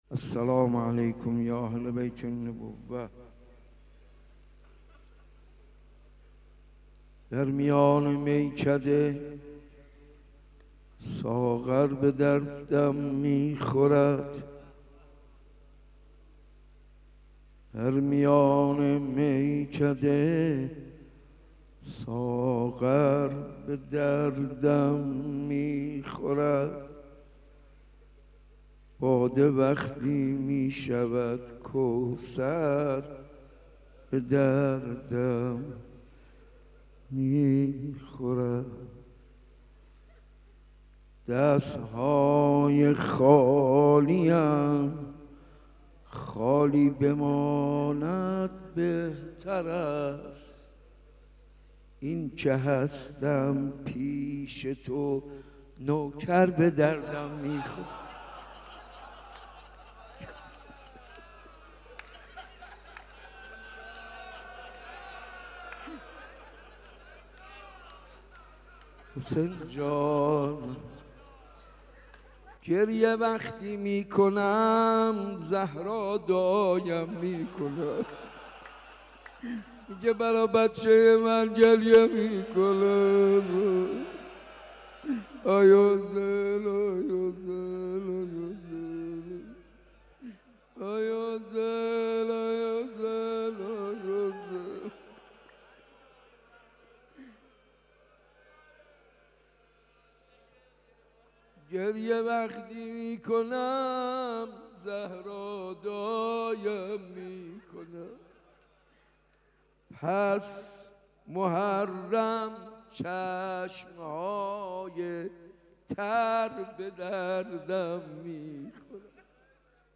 صوت مراسم شب سوم محرم ۱۴۳۷ مسجد ارگ ذیلاً می‌آید: .:اشکال در بارگذاری پخش کننده:.
حاج منصور ارضی-شب سوم محرم1437-مسجد ارک.mp3